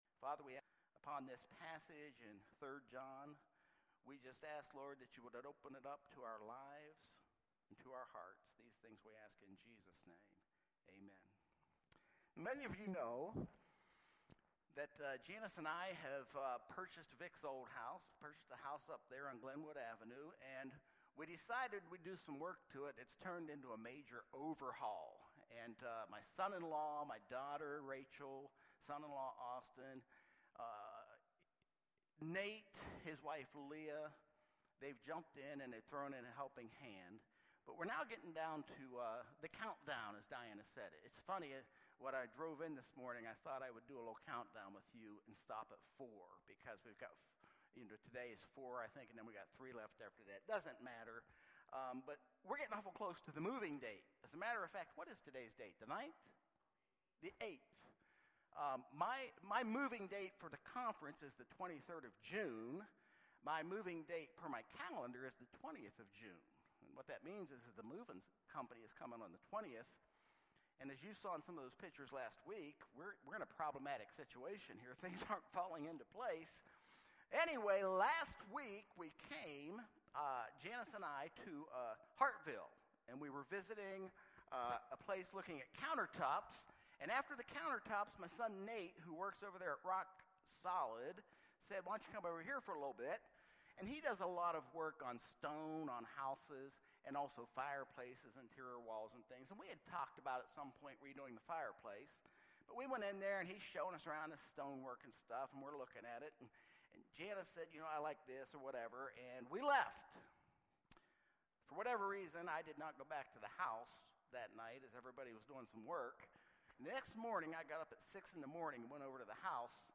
Sermons by Westbrook Park United Methodist Church